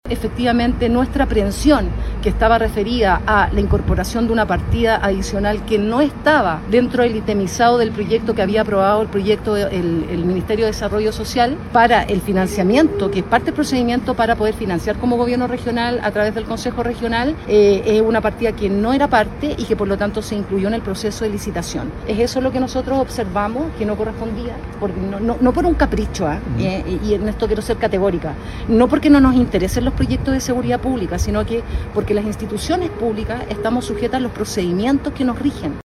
Escuchemos a la gobernadora subrogante Natalia Sánchez: